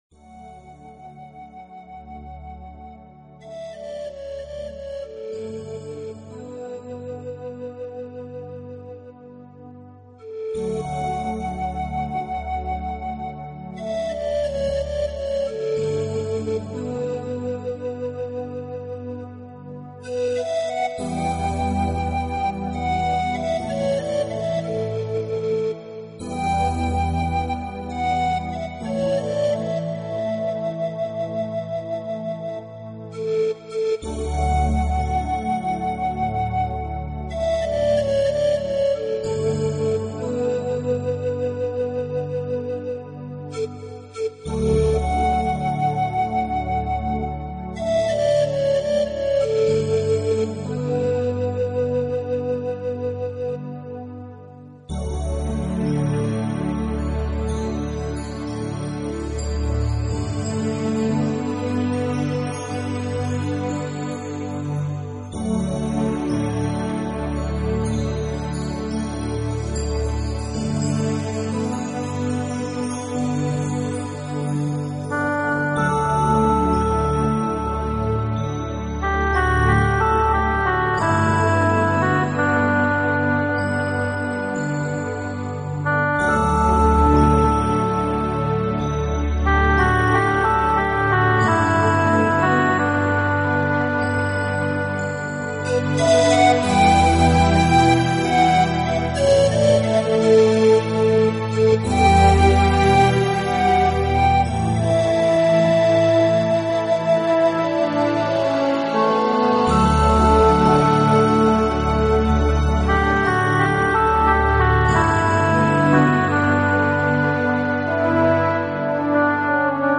新世纪纯音乐
专辑语言：纯音乐